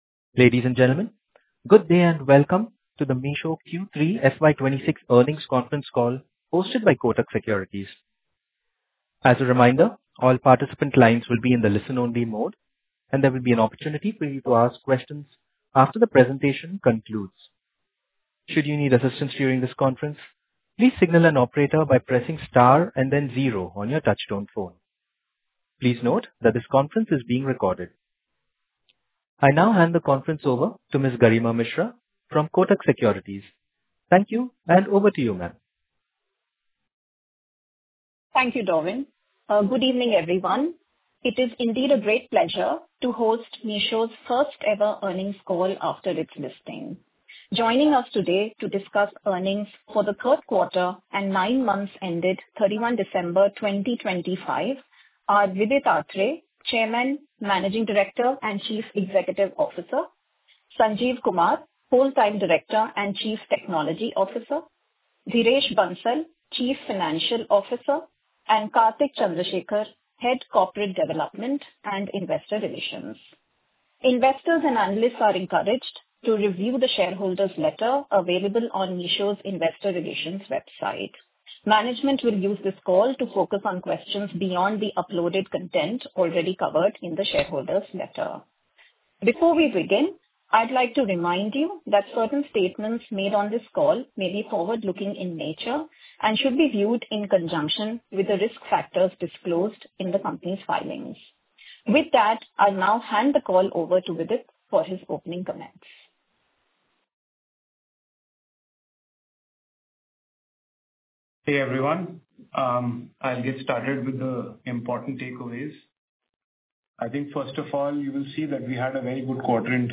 Concalls
q3-earnings-call.mp3